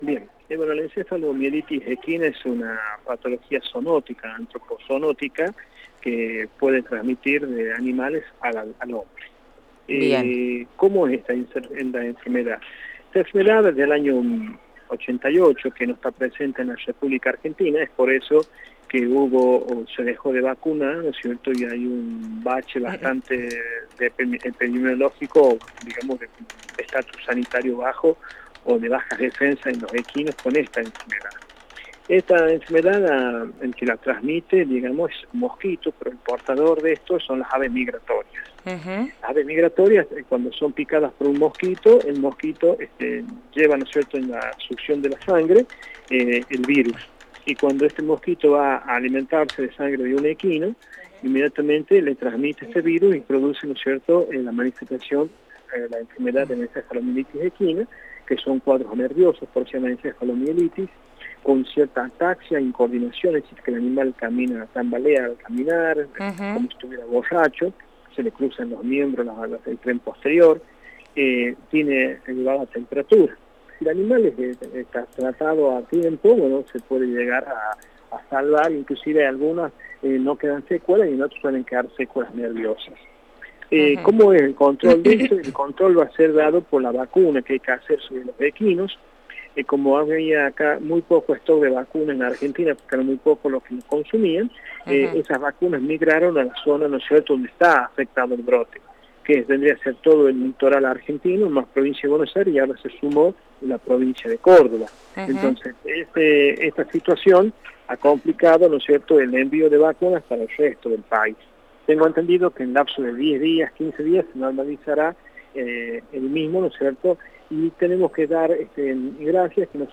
Al respecto Radio UNLaR dialogó con el Secretario de Ganadería Juan Carbel quien se refirió a las medidas que se adoptaron para preservar los equinos en la provincia hasta que adquieran inmunidad con la vacunación correspondiente.